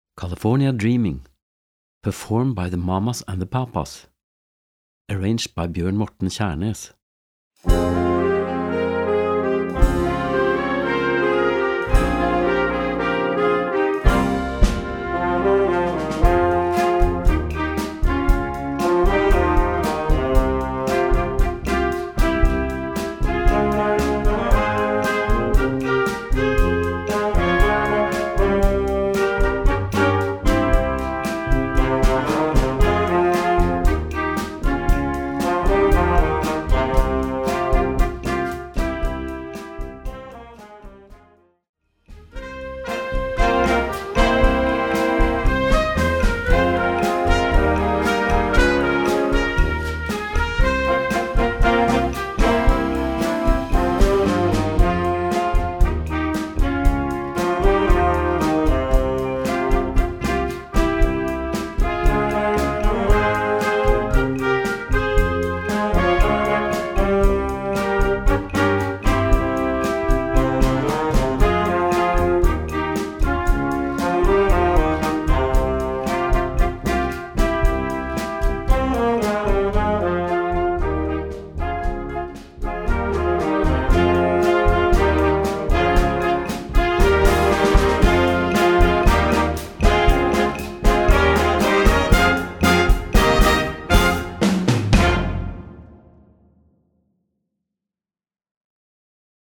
Evergreen für Jugendblasorchester
Besetzung: Blasorchester